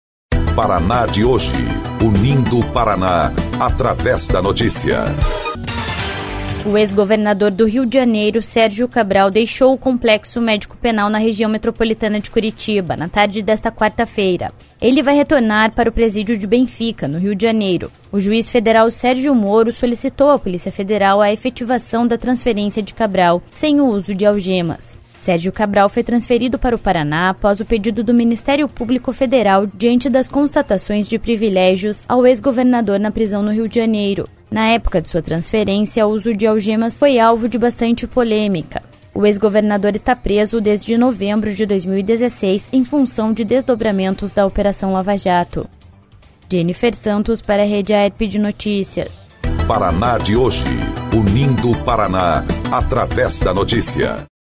11.04 – BOLETIM – Sérgio Cabral deixa presídio do Paraná